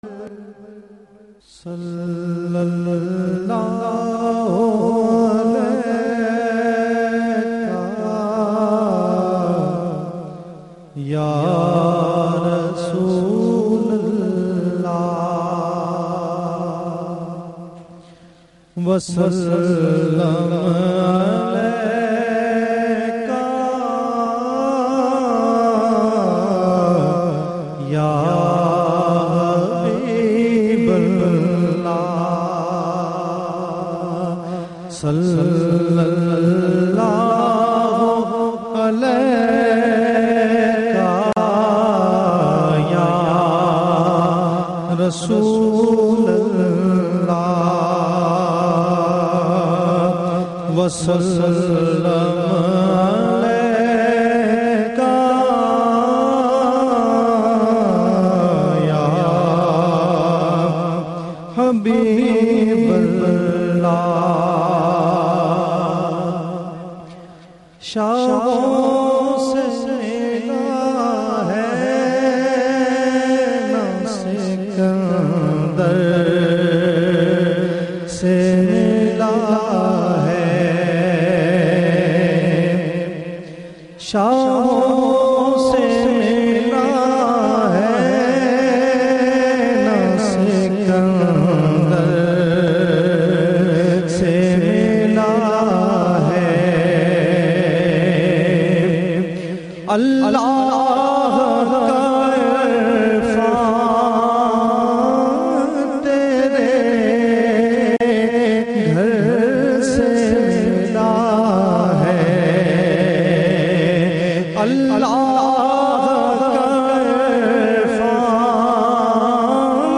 " Naat MP3